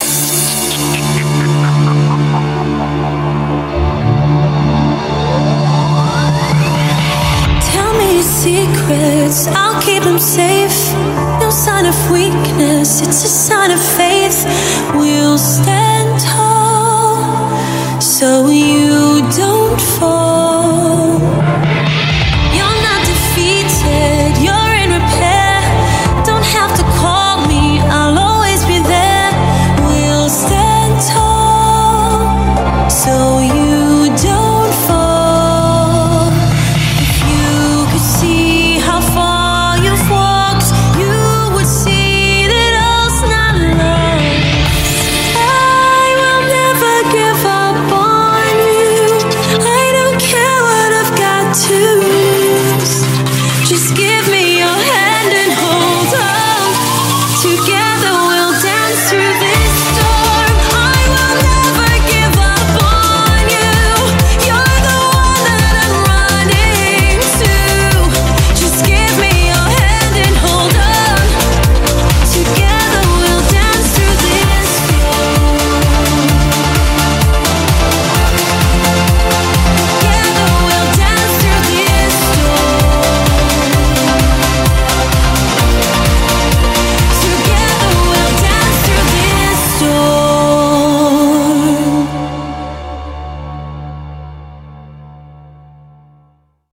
BPM129
Audio QualityMusic Cut
A nice house remix of that song.